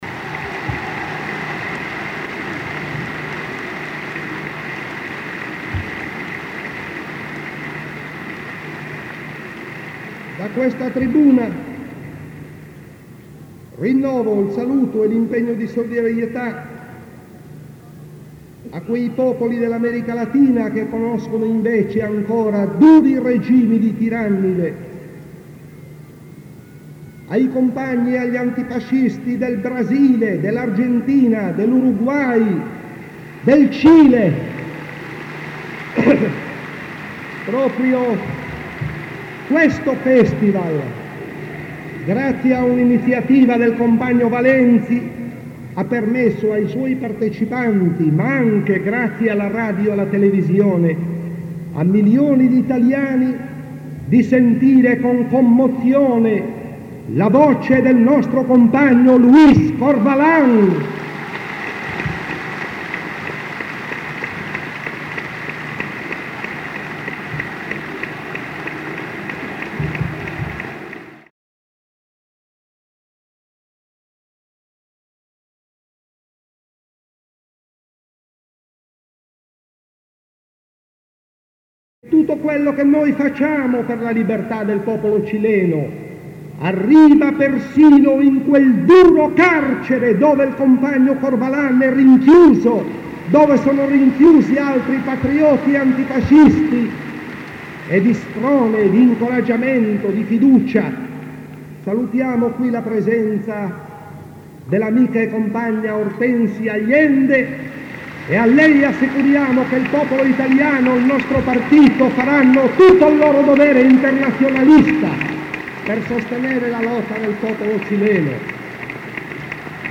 Comizio conclusivo della Festa nazionale dell’Unità